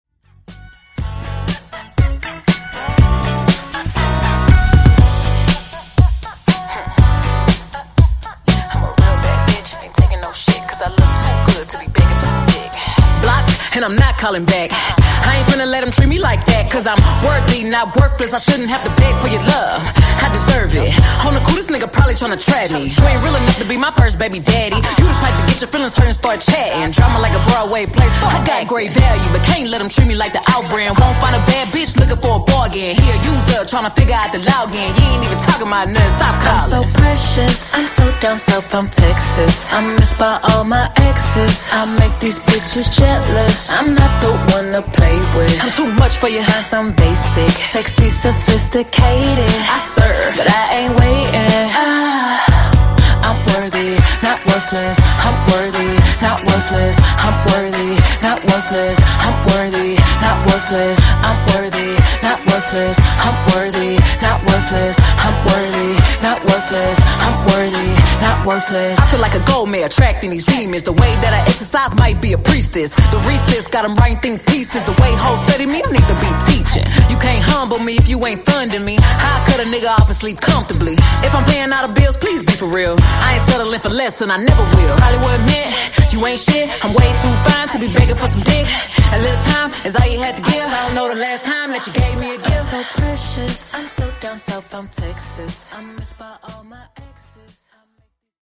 Genres: DANCE , RE-DRUM Version: Clean BPM: 132 Time